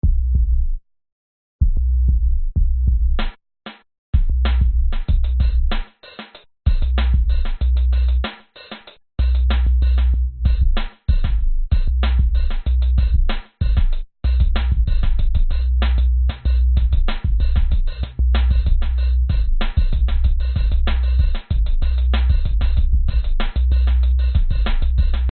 声道立体声